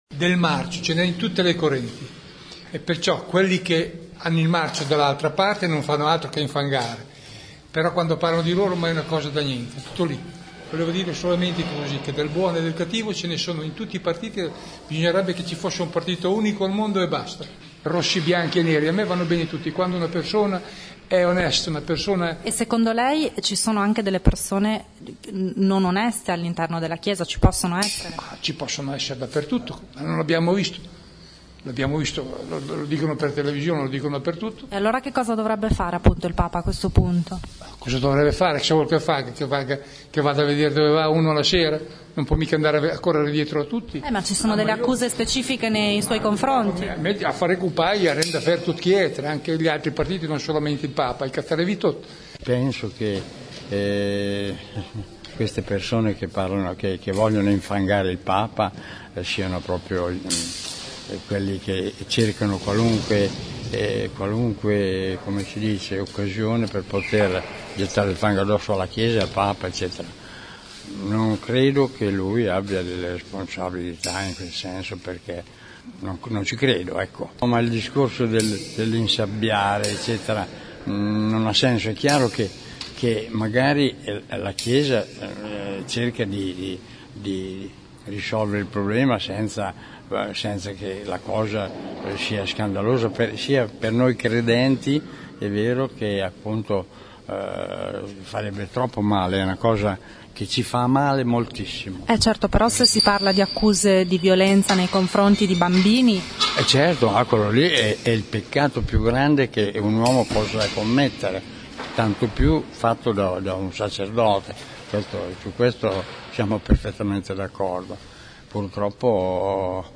29 mar.- Abbiamo fatto un giro tra i fedeli e i parrocchiani della chiesa di San Giovanni Cottolengo, in via Marzabotto.
Ascolta le voci dei fedeli: